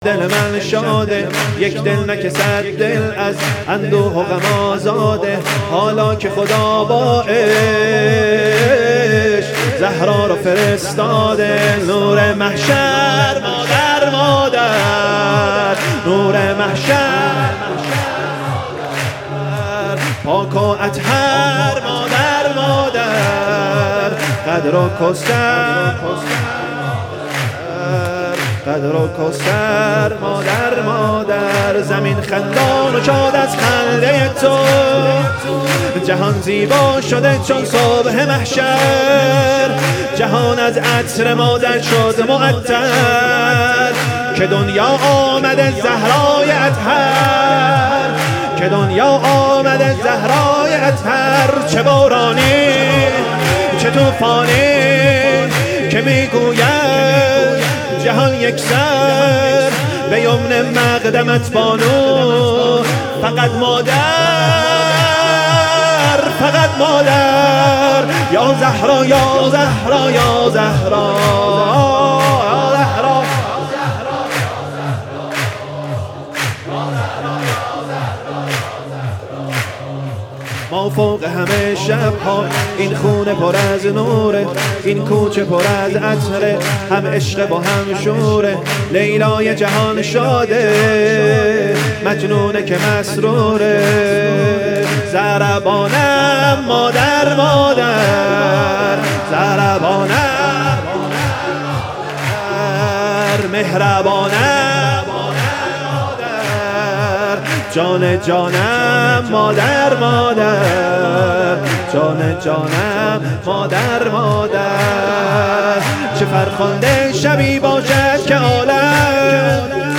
سرود یک دل نه صد دل
ولادت حضرت زهرا(س)